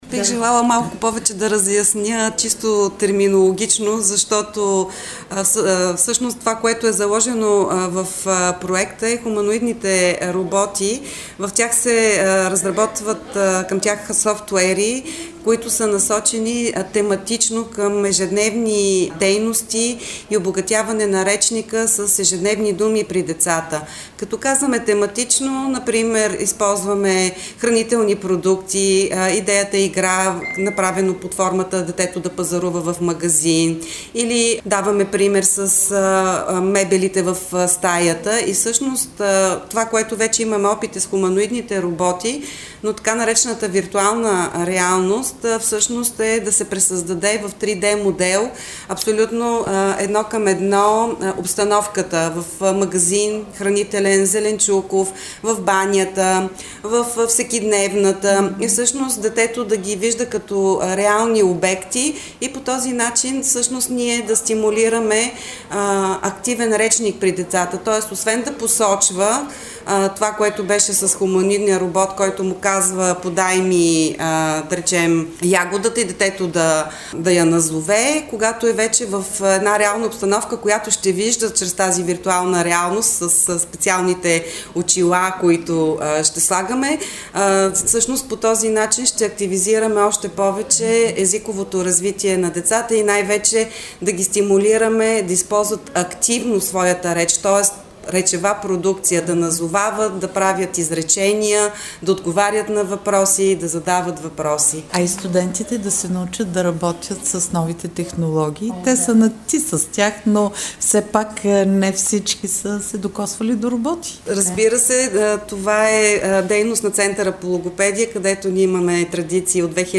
Същността на проекта обясни в ефира на Радио Благоевград